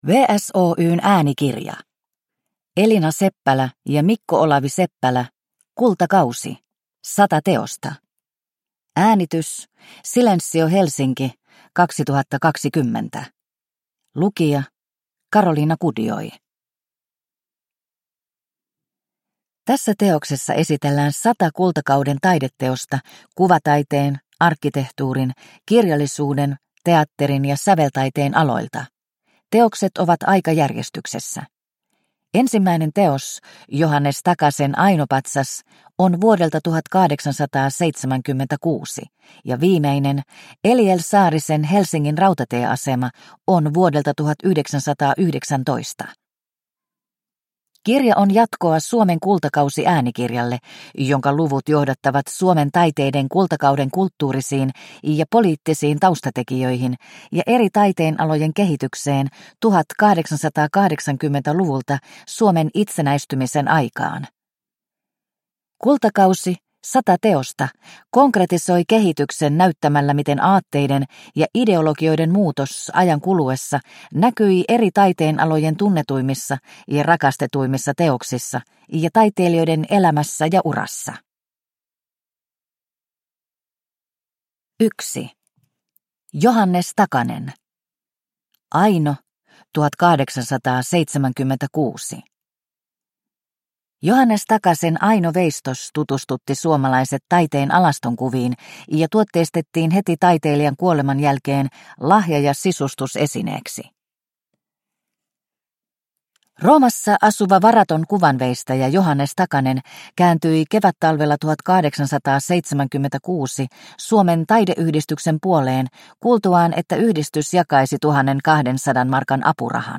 Kultakausi: 100 teosta – Ljudbok – Laddas ner